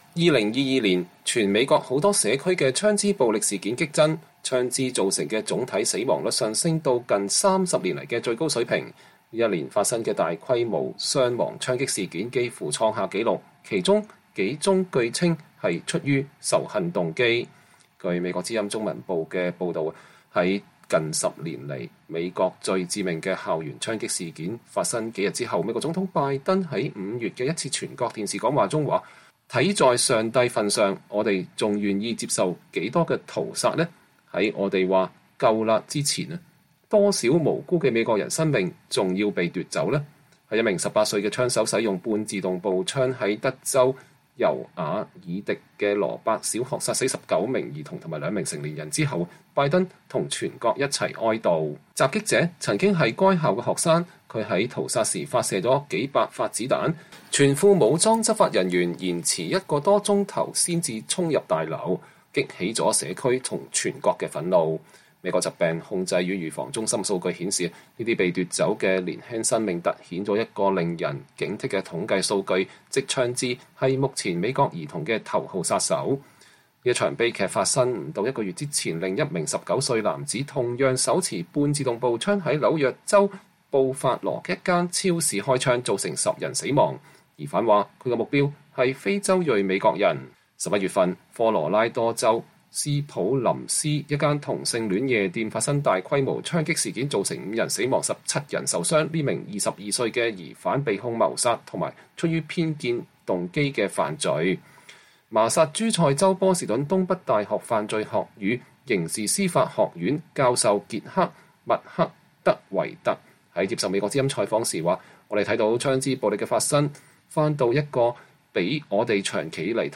年終報導：美國2022年槍支暴力大幅上升